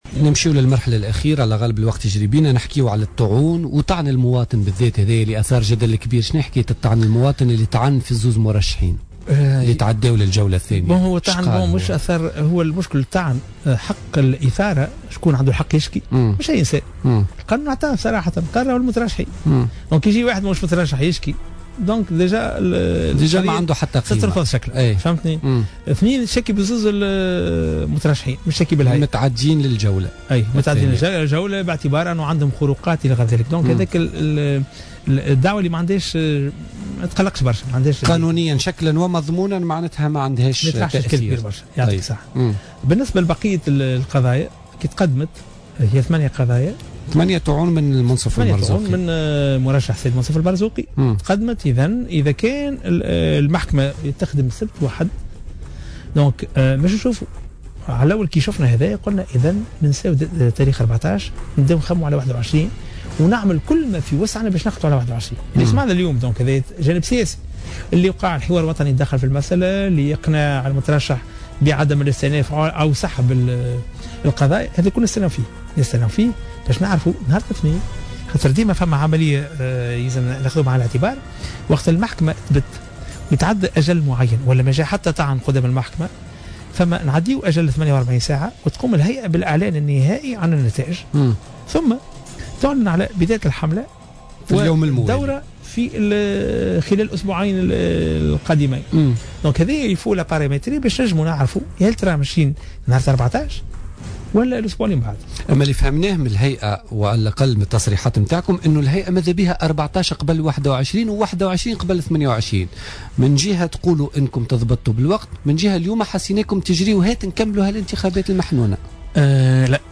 وقال صرصار في حوار أجرته معه إذاعة الجوهرة أف أم اليوم الاثنين أن الأمن لا يستطيع تأمين الانتخابات وتعزيز تواجده بمناسبة رأس السنة في نفس الوقت وهو يجعل أمن البلاد مهددا بسبب تشتت المساعي الأمنية.